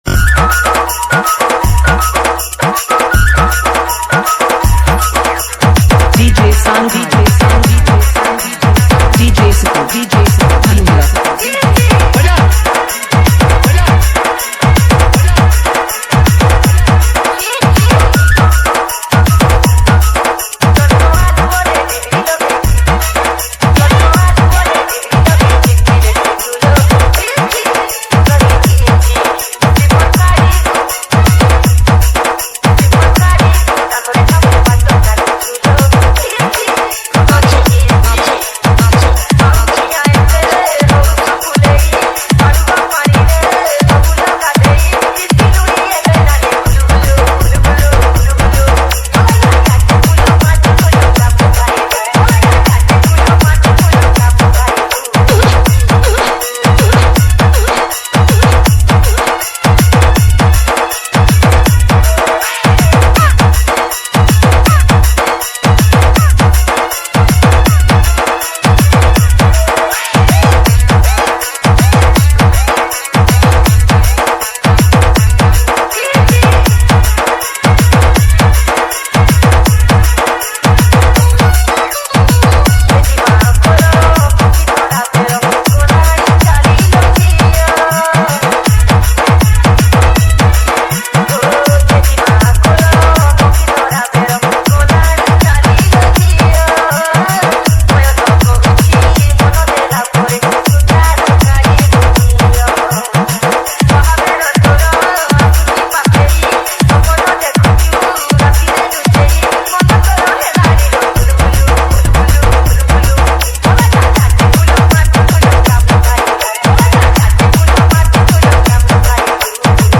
Category:  Odia Old Dj Song